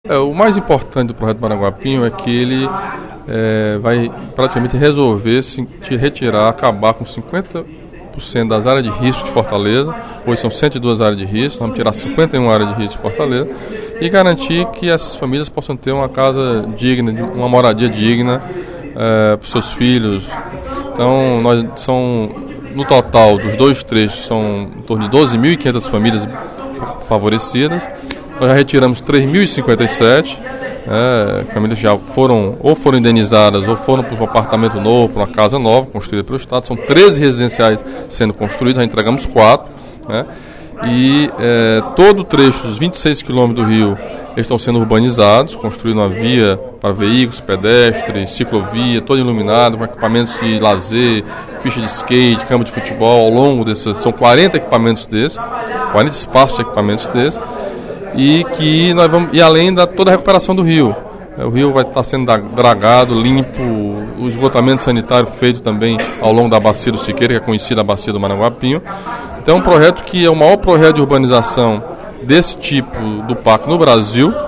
O deputado Camilo Santana (PT) disse, nesta quarta-feira (27/06) em plenário, que o projeto do rio Maranguapinho eliminará 50% das áreas de risco de Fortaleza.